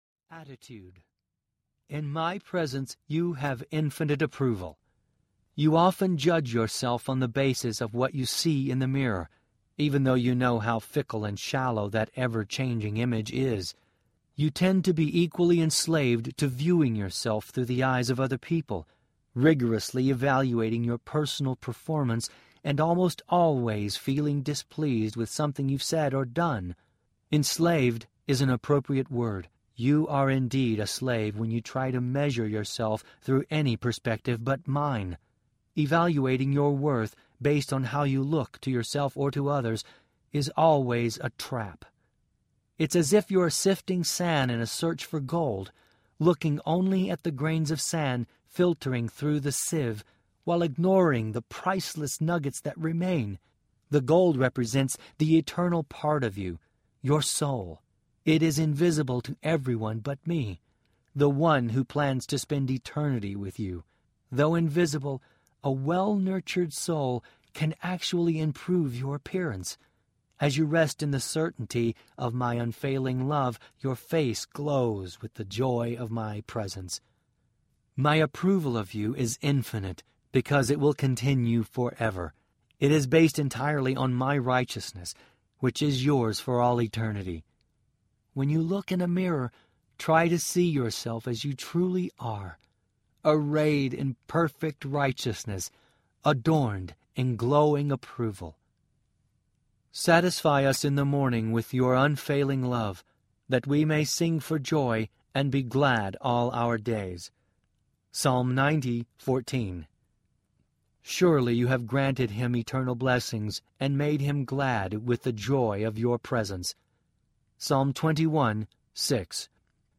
Jesus Lives Audiobook
Narrator
7.2 Hrs. – Unabridged